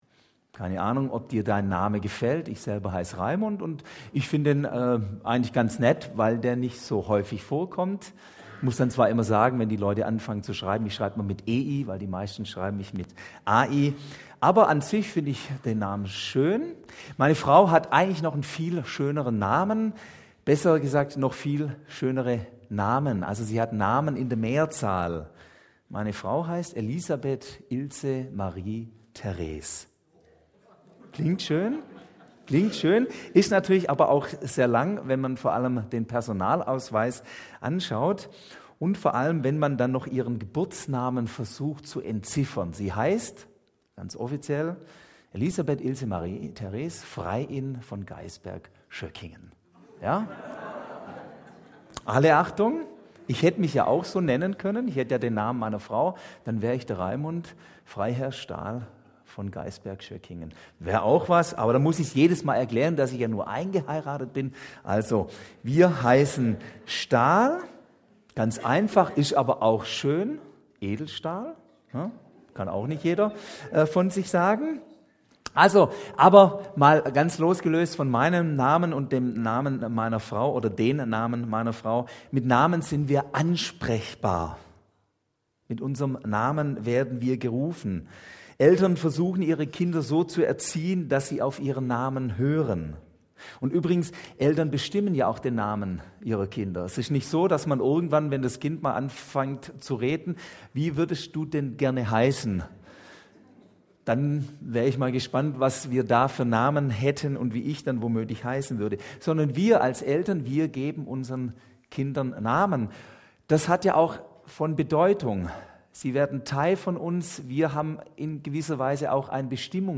Predigt 21.04.2024 - SV Langenau